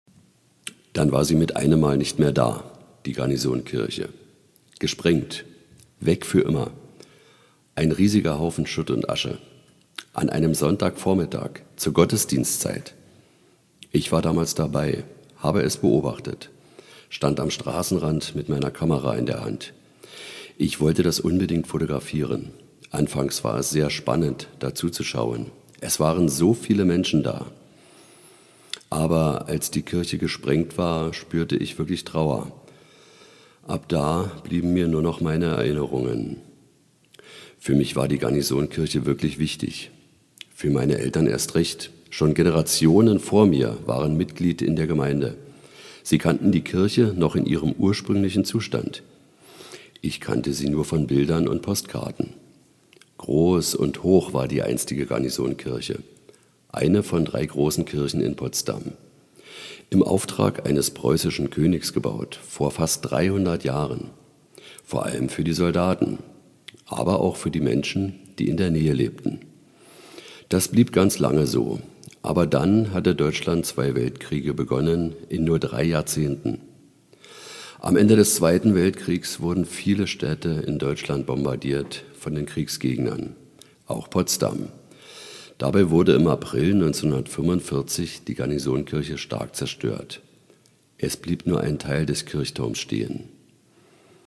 Werbesprecher
Sprachproben
Male